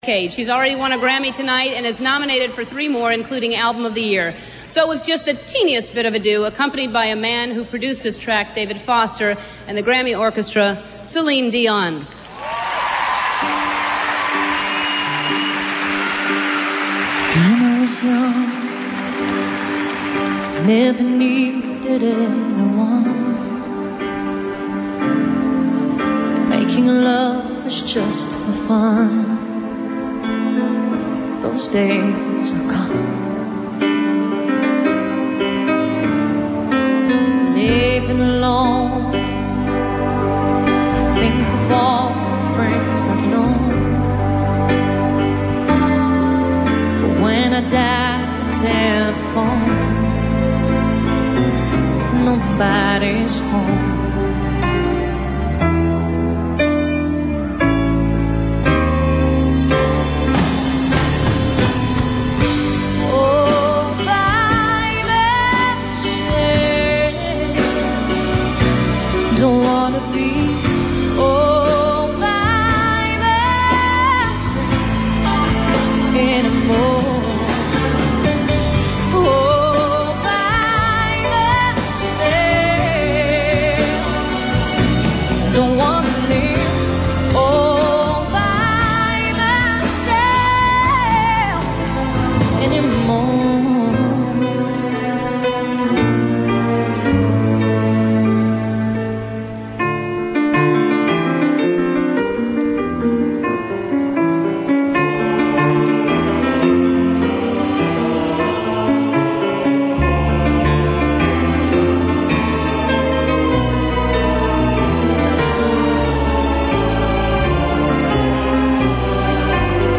Téléchargez  Live aux Grammys 1997 (26 fevrier)